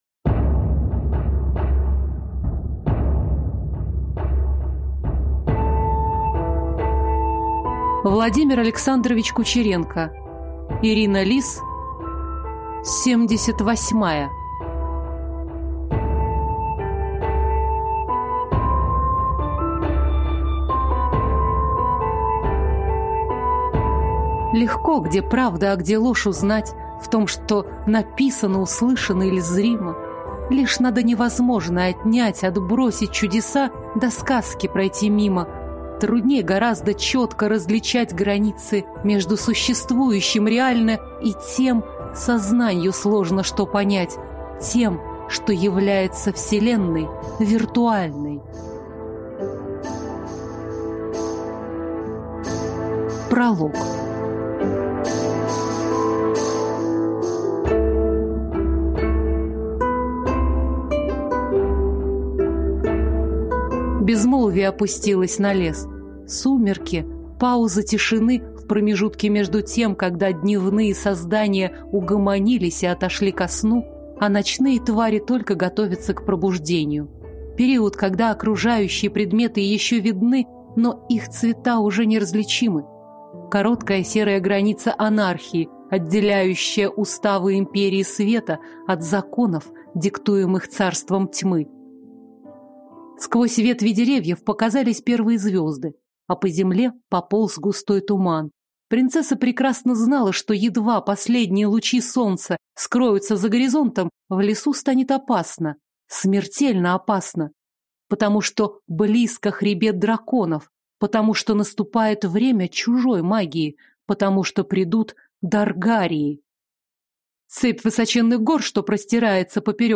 Аудиокнига Семьдесят восьмая | Библиотека аудиокниг